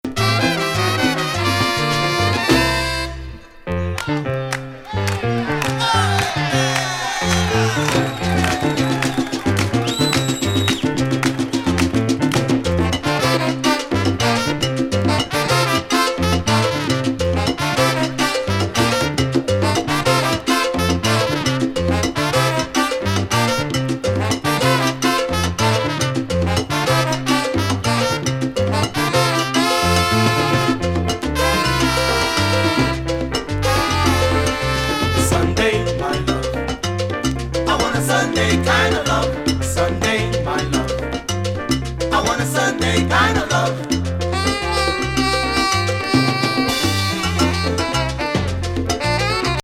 GROOVYナンバー多数!